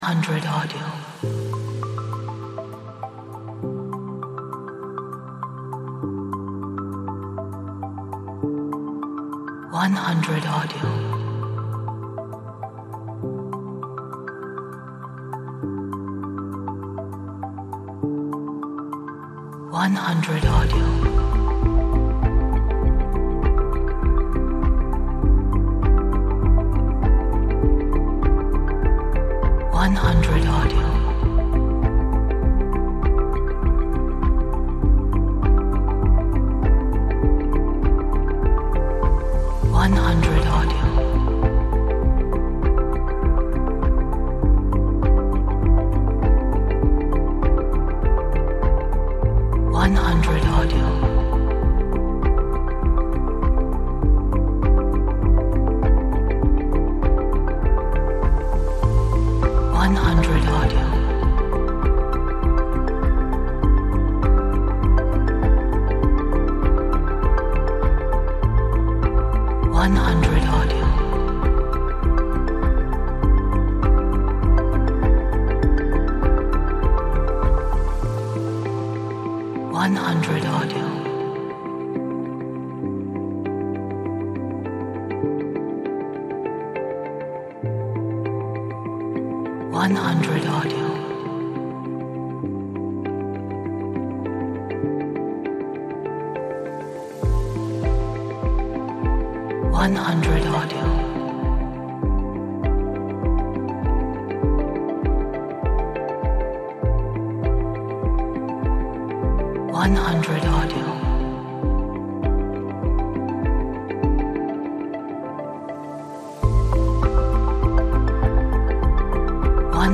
Relaxing ambient track.